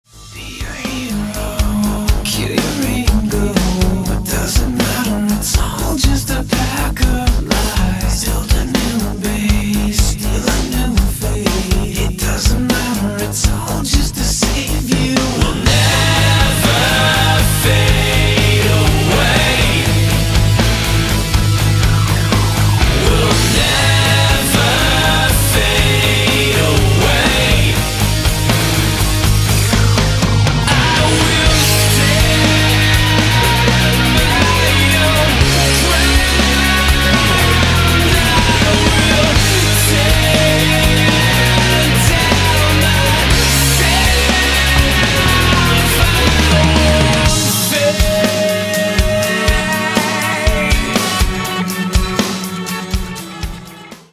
мужской вокал
рок
альтернативный метал